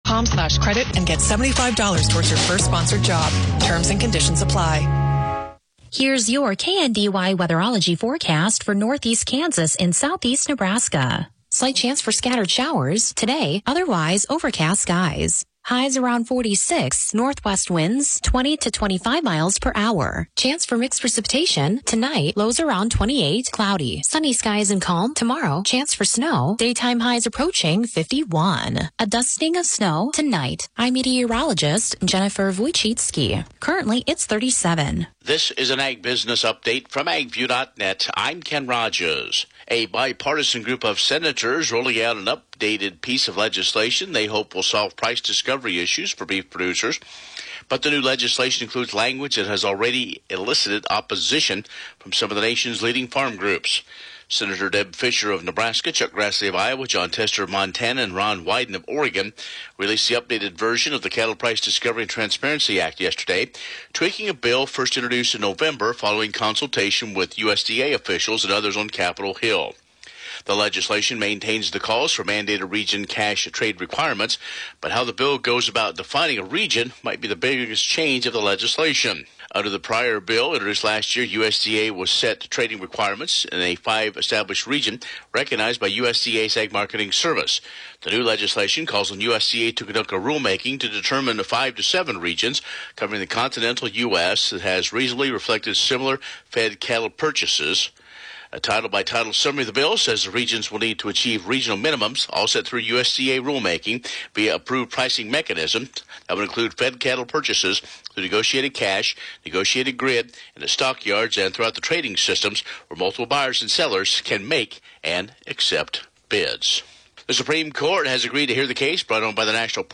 Broadcasts are archived daily as originally broadcast on Classic Country AM 1570/FM 94.1 KNDY.